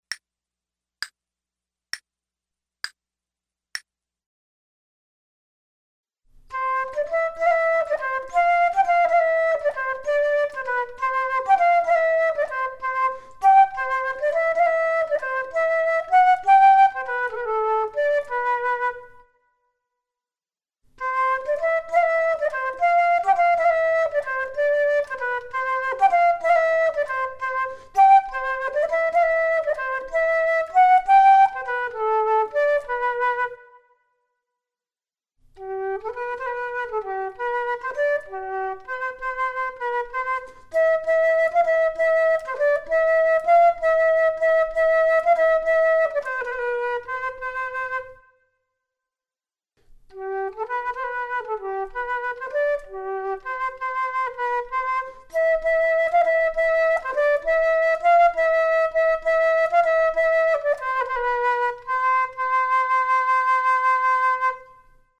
Flute 2 Only:
It's another lively duet in 6/8 time.
The metronome setting for this performance is dotted quarter note = 66.